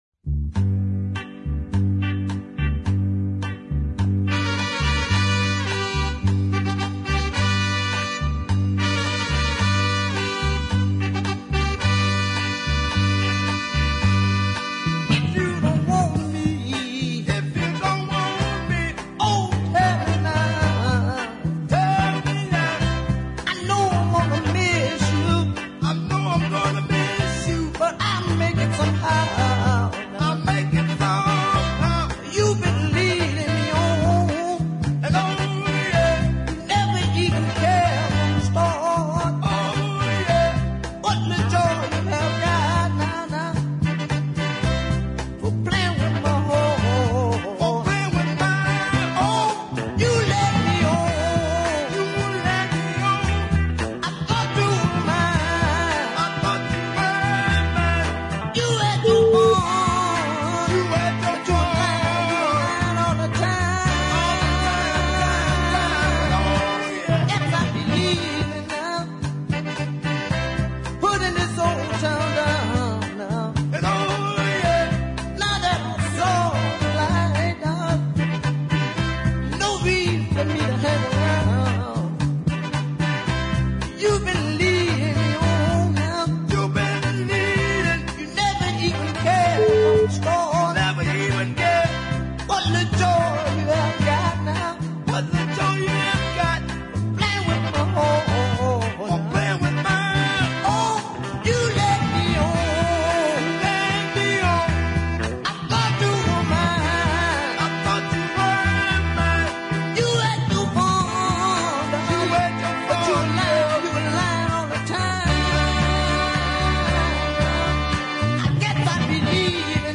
mid paced
southern soul. Great rhythm and horns of course
gritty vocal, chock full of Otis Redding touches